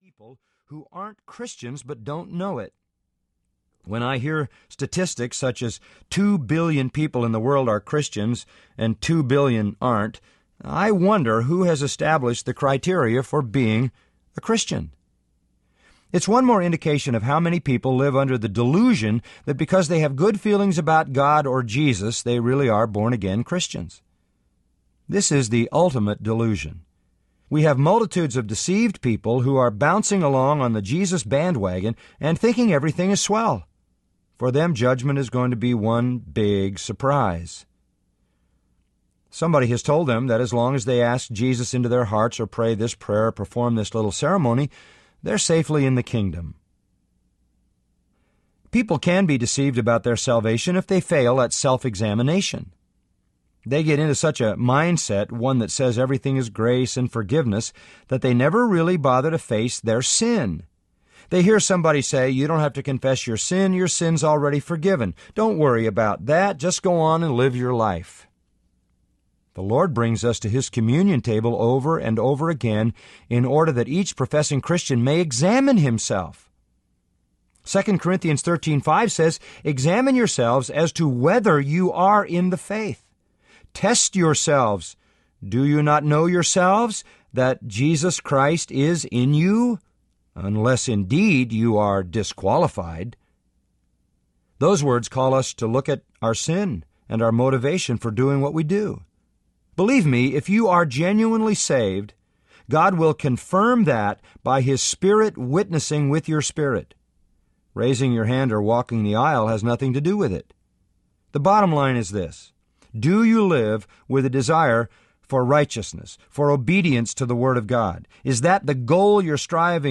Hard to Believe Audiobook
3.75 Hrs. – Unabridged